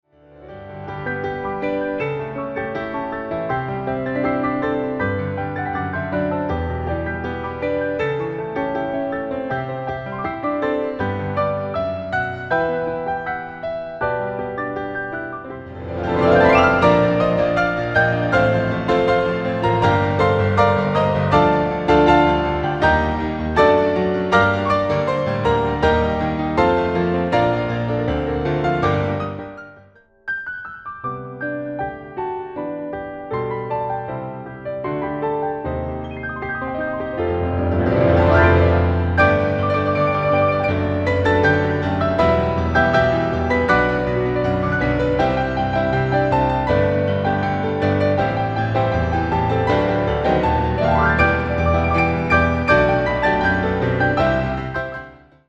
ピアノ連弾試聴